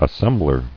[as·sem·bler]